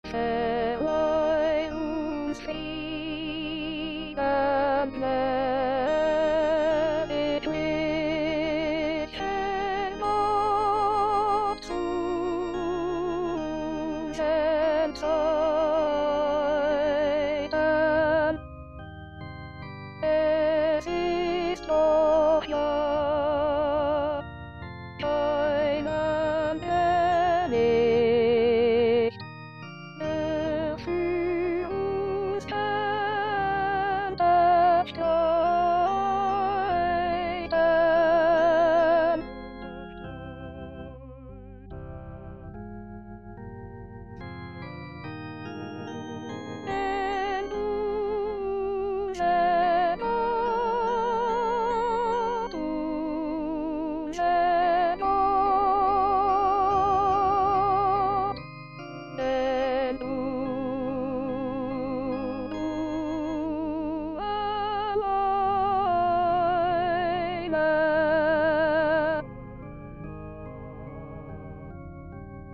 B: Choeur à 4 voix:  70-98